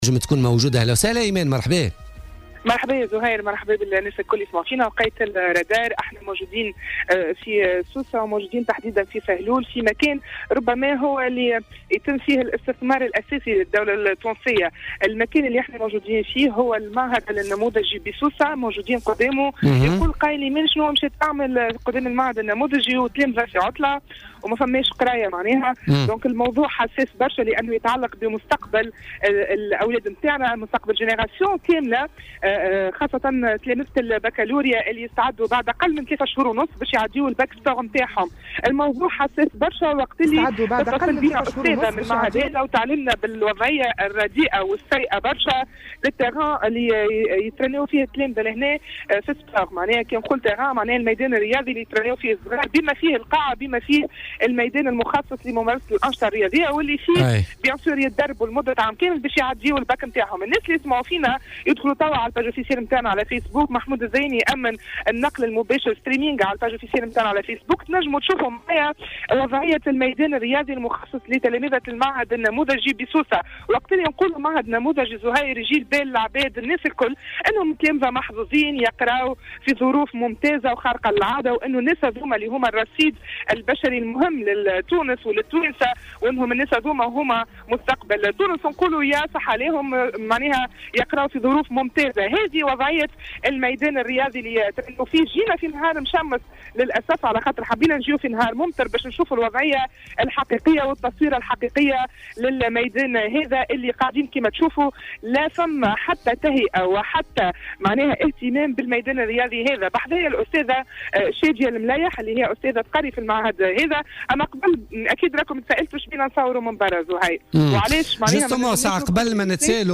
انتقل فريق "الرادار" اليوم الاثنين إلى مقر المعهد النموذجي بسوسة الكائن بمنطقة سهلول.